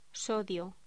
Locución: Sodio